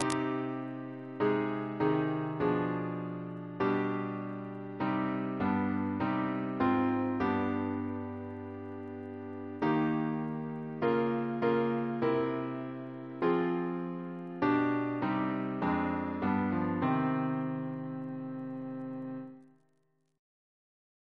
CCP: Chant sampler
Double chant in C minor Composer: Sir John Goss (1800-1880), Composer to the Chapel Royal, Organist of St. Paul's Cathedral Reference psalters: ACB: 55; CWP: 182; PP/SNCB: 138